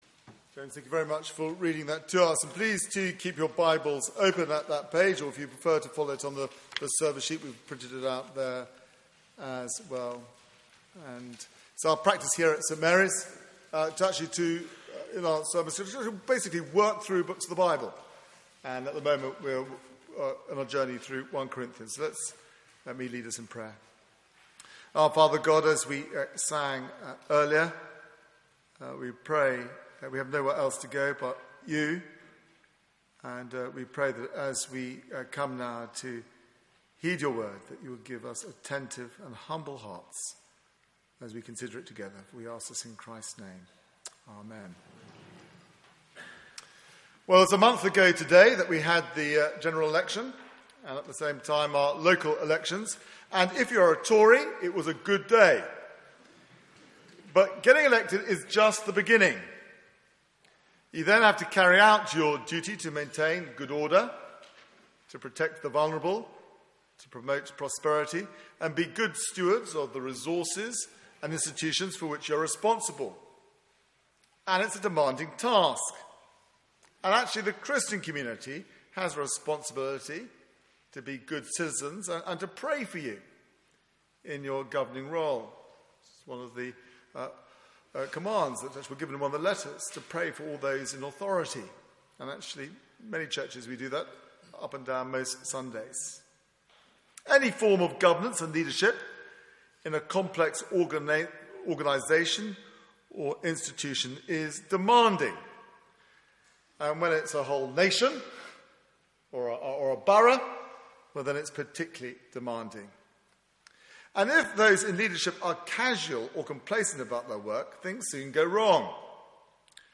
Media for 9:15am Service on Sun 07th Jun 2015 09:15
Passage: 1 Corinthians 10:1-13 Series: A CHURCH WITH ISSUES Theme: Forewarned is forearmed Sermon